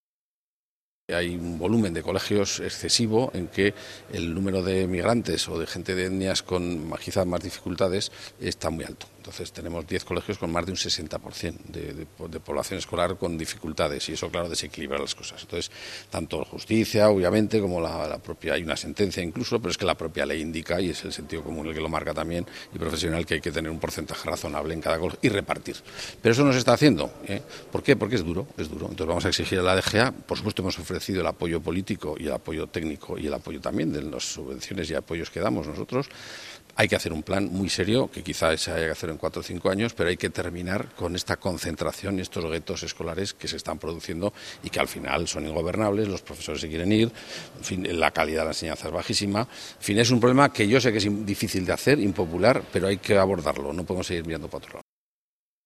Una situación a atajar, en opinión de Jerónimo Blasco: